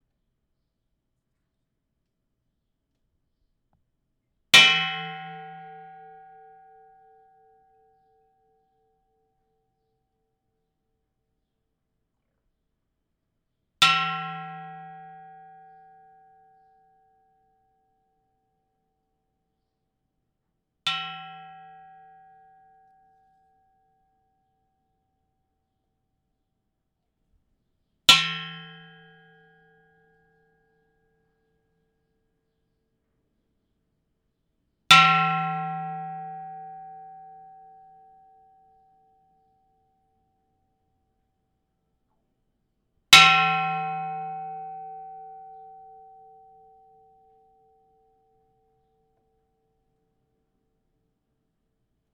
Metal_clang
bang clang metal pan pot sound effect free sound royalty free Memes